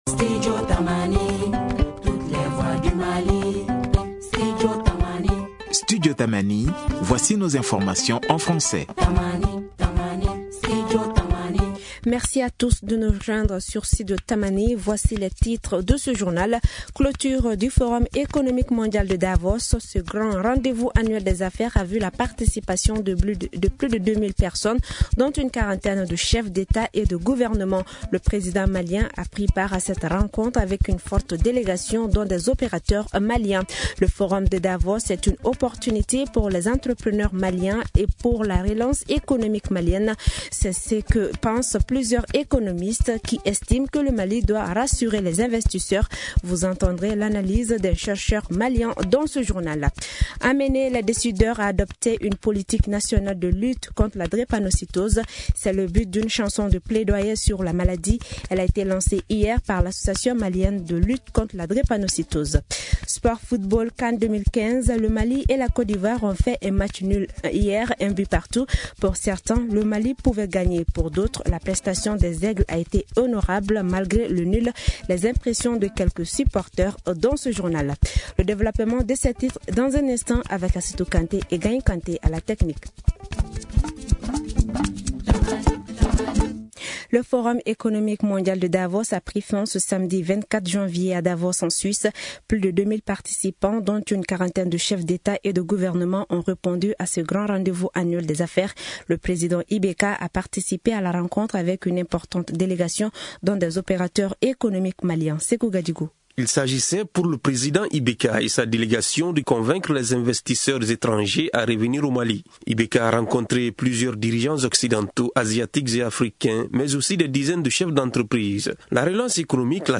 Journal en français: Télécharger
Vous entendrez l’analyse d’un chercheur malien dans ce journal.
Les impressions de quelques supporters dans ce journal.